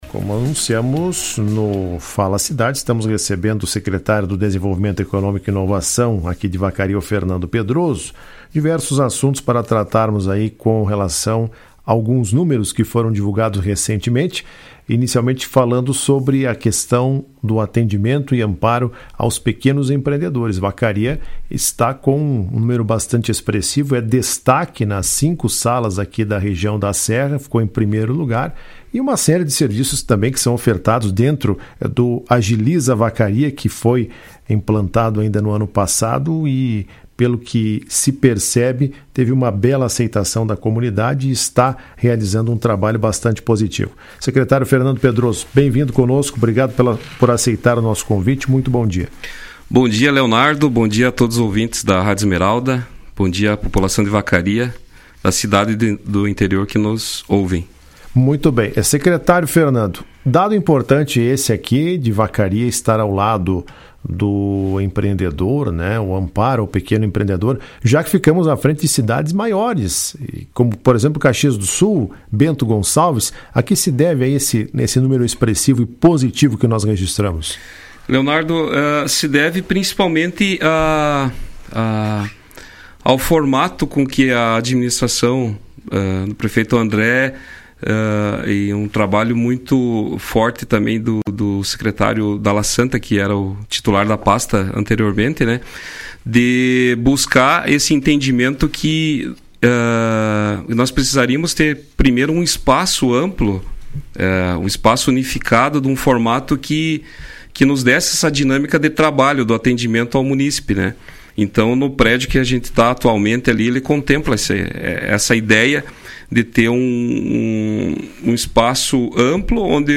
Em entrevista à Rádio Esmeralda na manhã desta quarta-feira (08), o Secretário de Desenvolvimento Econômico e Inovação, Fernando Pedroso, celebrou os números expressivos da Sala do Empreendedor de Vacaria.